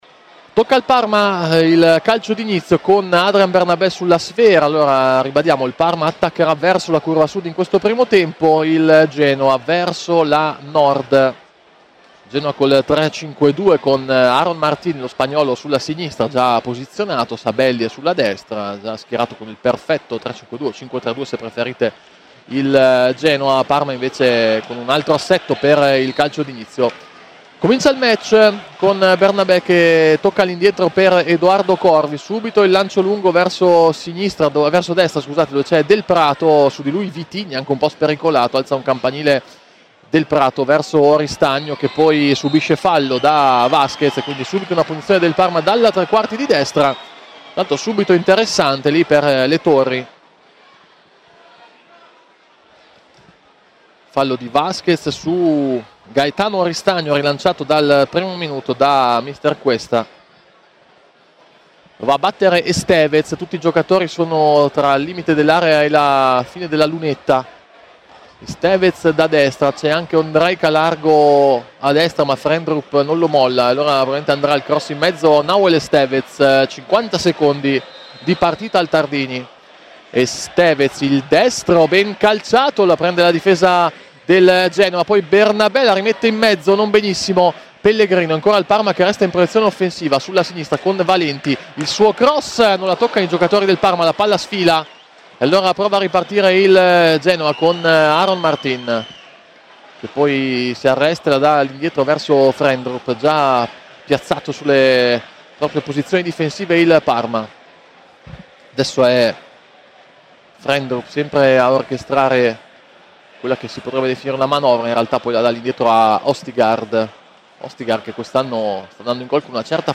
Il Parma a quota 22, Genoa a 19, entrambe le squadre sono in un buon momento con 4 punti nelle ultime due gare. Radiocronaca